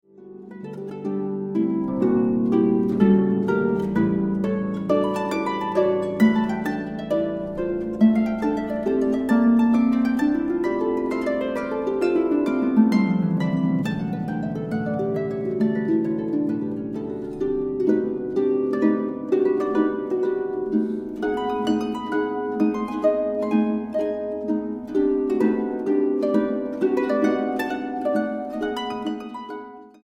arpa.